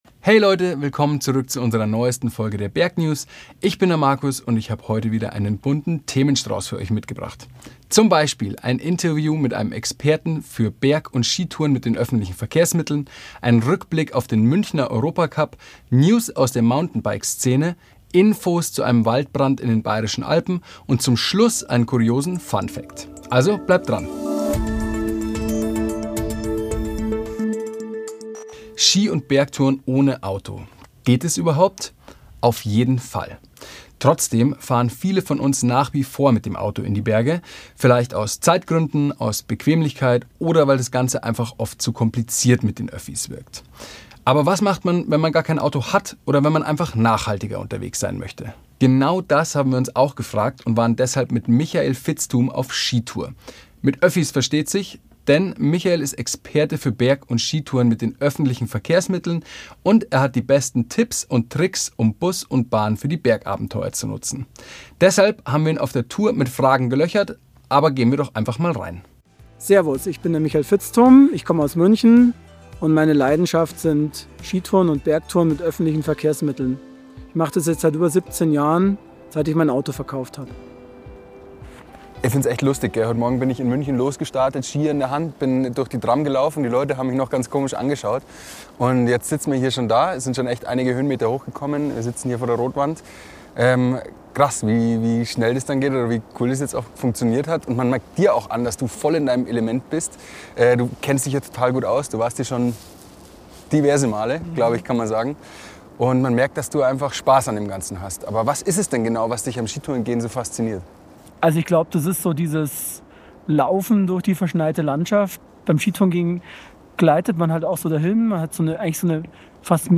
erzählt er im Interview.